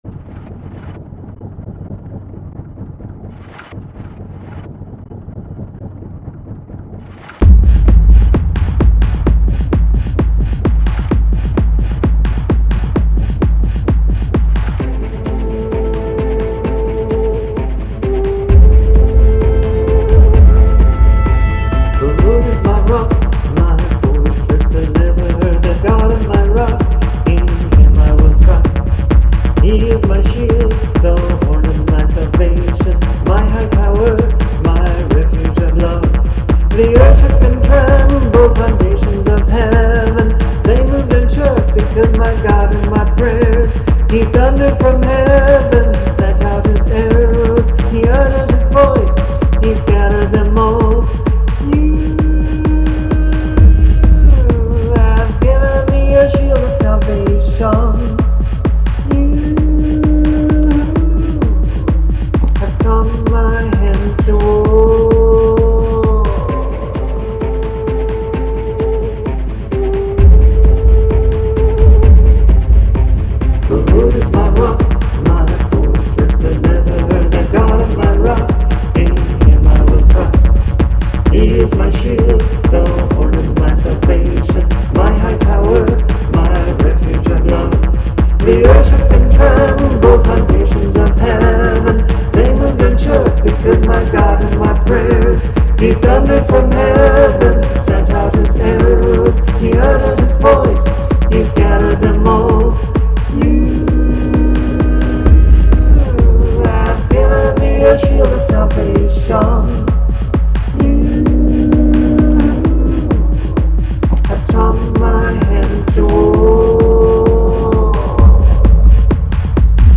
extreme low tones and bass ... and these will not play